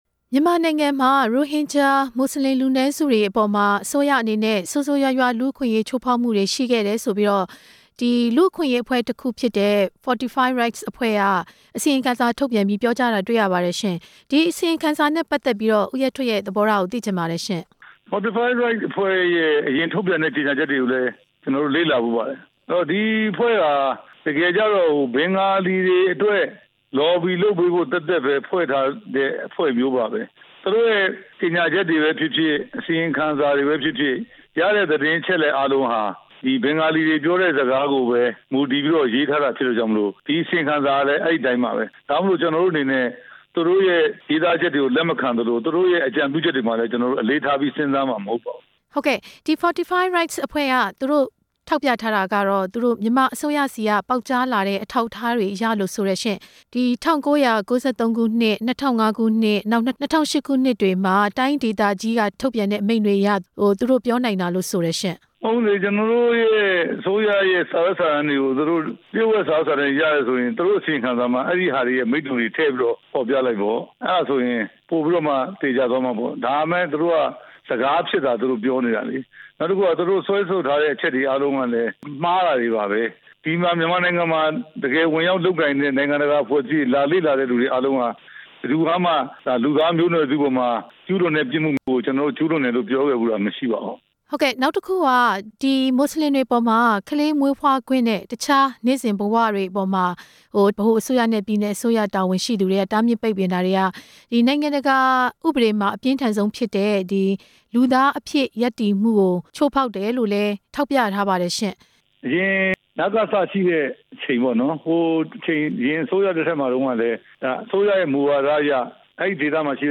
ပြန်ကြားရေး ဒုဝန်ကြီး ဦးရဲထွဋ်နဲ့ မေးမြန်းချက်